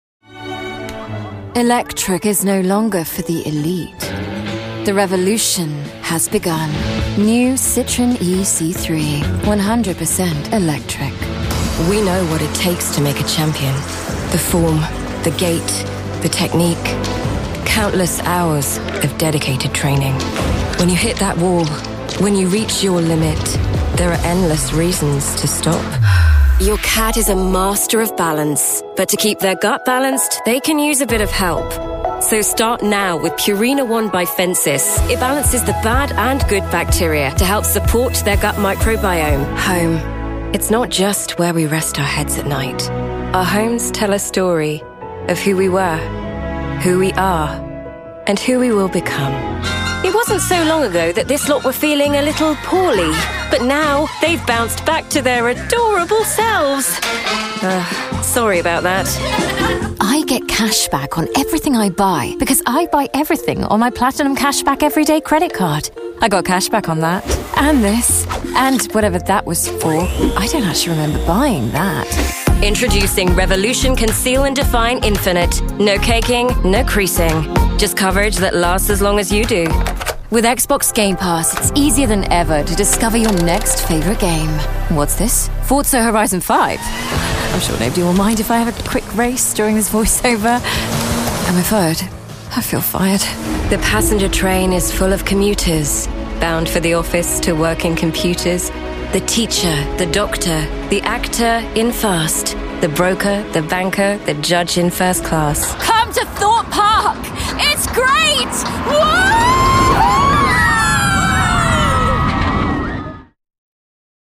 Inglês (britânico)
Amigáveis
Conversacional
Com experiência